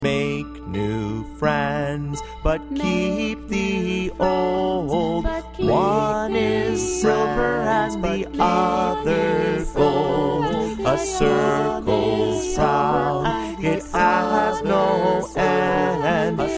Vocal Song Downloads